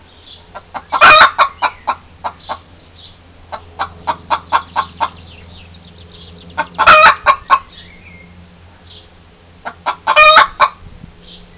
6 Long ago (well, early last year actually), when Frida was still with us (see About Us if you don't know Frida), Aggie and Frida used to announce when they laid an egg.  Aggie hasn't done this for quite some time, but this morning I thought she was never going to stop!
Aggie's announcement.